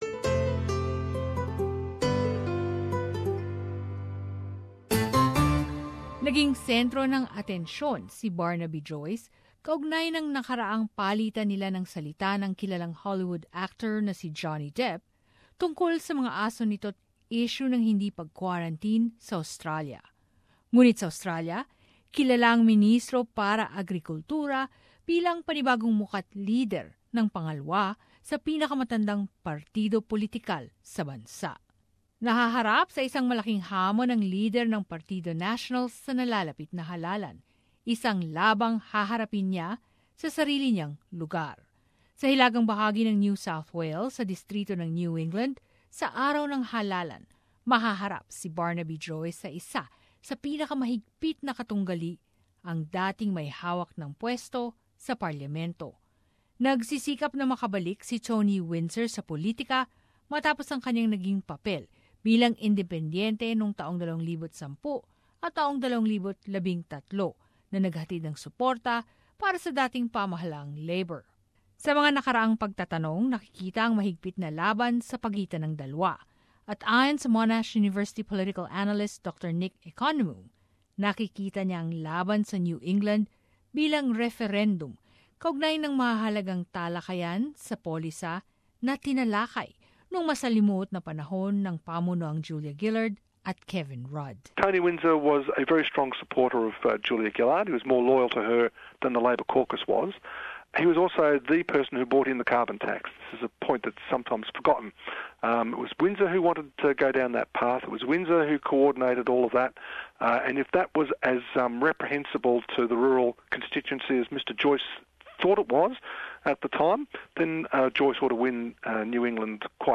But as this report shows, leader Senator Barnaby Joyce has a fight on his hands in his own New South Wales seat of New England.